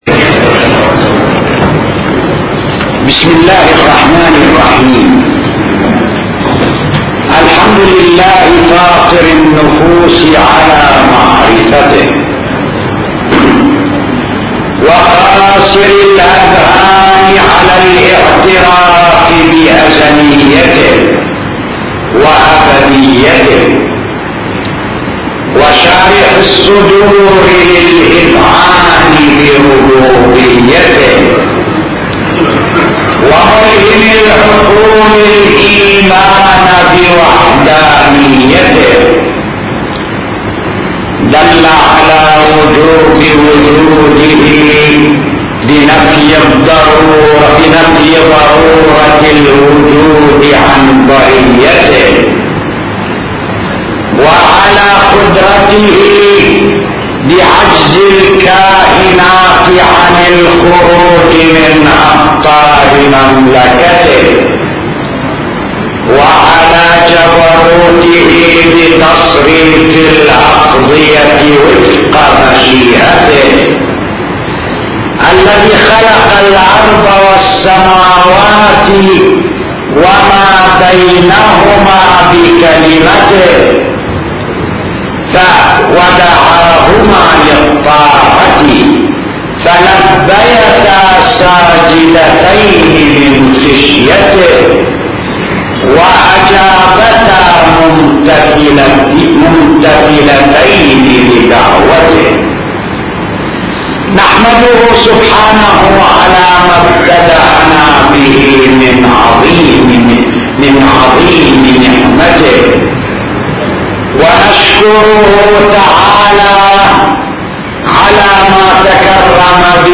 خطب